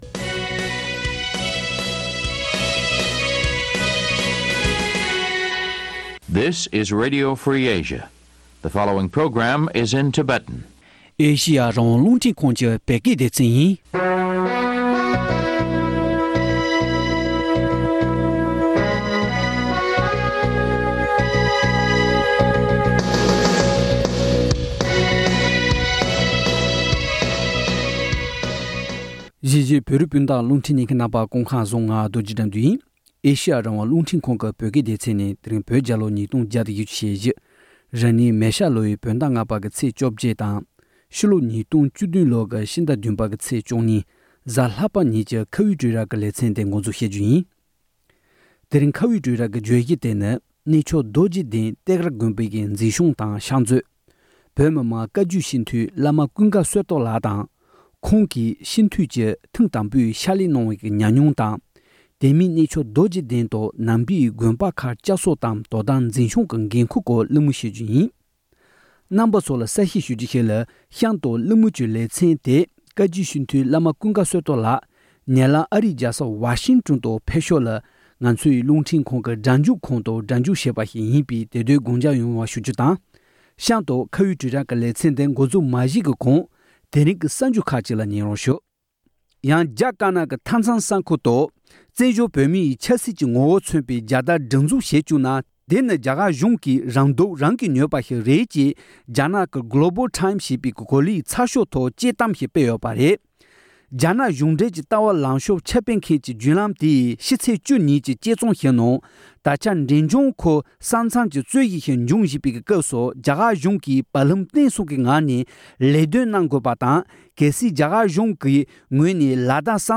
བཀའ་བརྒྱུད་སྤྱི་འཐུས་བླ་མ་ཀུན་དགའ་བསོད་སྟོབས་ལགས་སུ་བཅར་འདྲི།